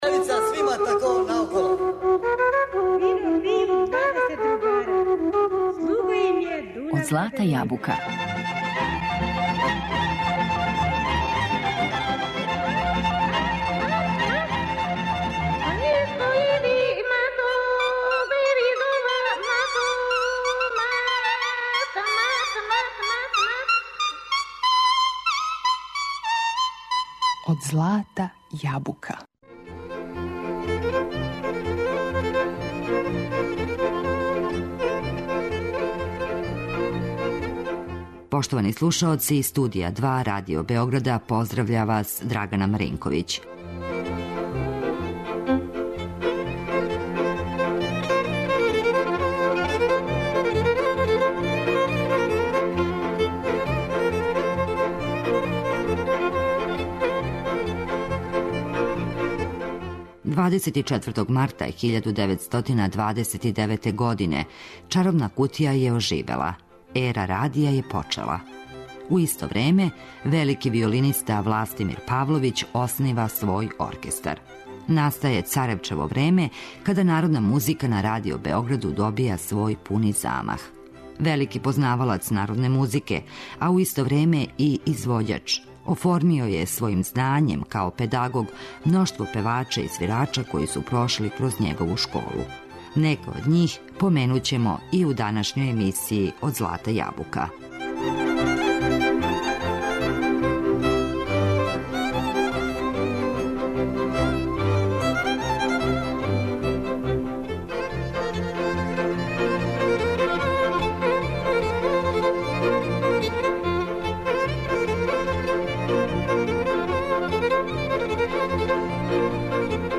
Чућемо песме у извођењу Николе Колаковића, Александра Дејановића, Данице Обренић, Дивне Ђоковић, Иване Пандуровић, Радмиле Димић и других.